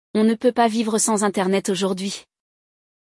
No episódio de hoje, você vai ouvir uma conversa entre uma jovem e seu pai sobre a necessidade de assinar um plano de internet para a casa.